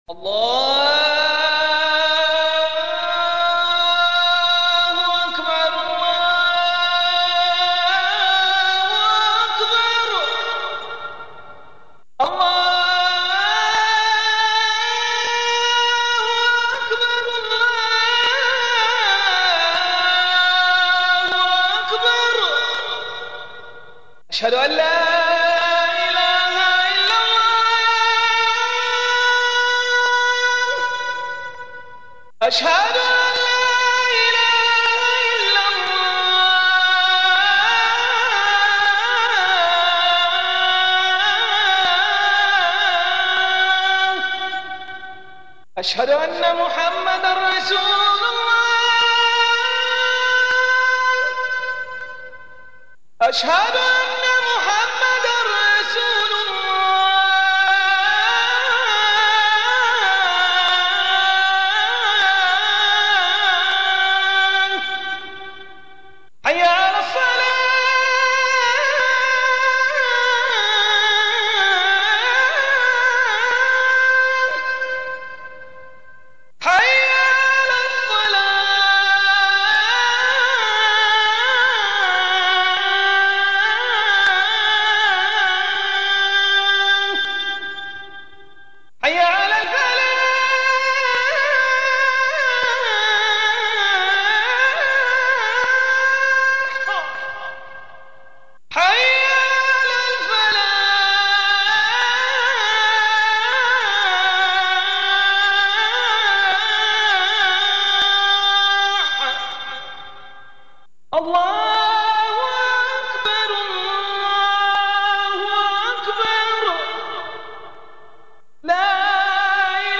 اذان مدینه
توضیحات اذانی که در مدینه می گفتند..
azane-madineh.mp3